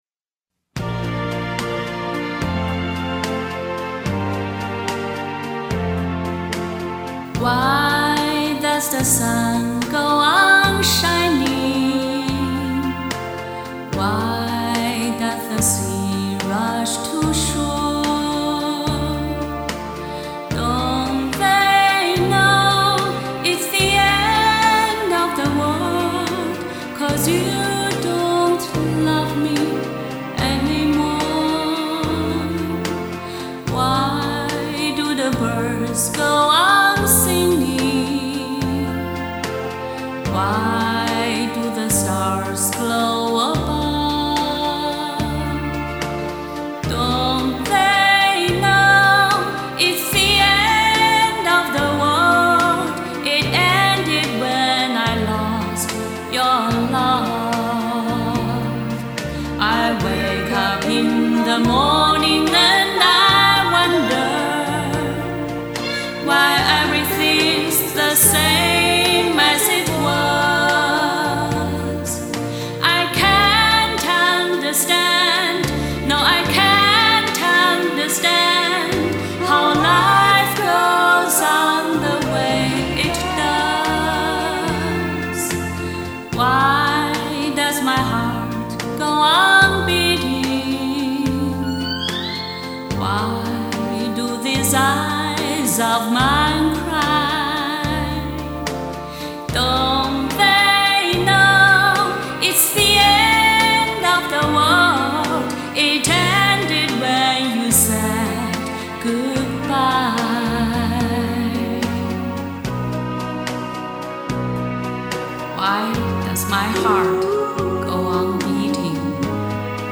今天趁着热乎劲儿赶快找来伴奏录了。即兴之作肯定毛病多多，心情太好时唱不出那份凄凉来，还请见谅～～